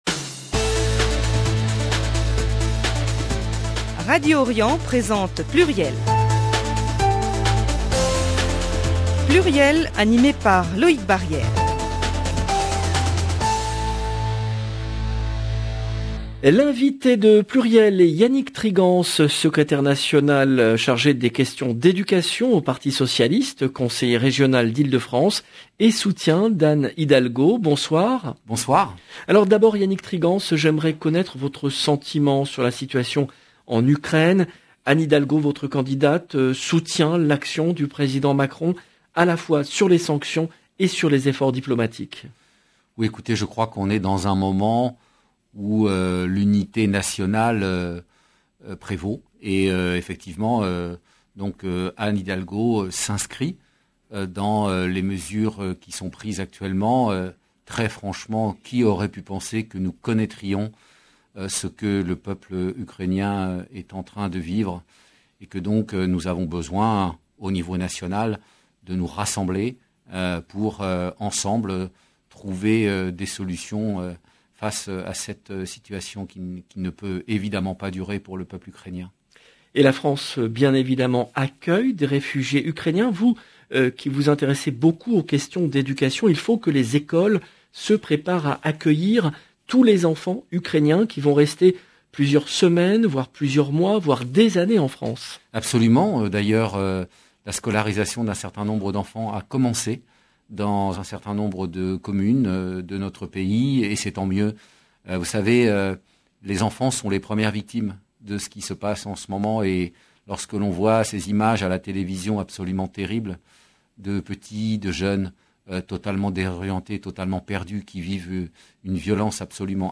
Yannick Trigance 16 mars 2022 - 19 min 29 sec Yannick Trigance, secrétaire national du PS chargé des questions d’éducation LB PLURIEL, le rendez-vous politique du mercredi 16 mars 2022 L’invité de PLURIEL est Yannick Trigance, secrétaire national du PS chargé des questions d’éducation, conseiller régional d’Ile-de-France et soutien d’Anne Hidalgo.